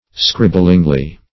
scribblingly - definition of scribblingly - synonyms, pronunciation, spelling from Free Dictionary Search Result for " scribblingly" : The Collaborative International Dictionary of English v.0.48: Scribblingly \Scrib"bling*ly\, adv.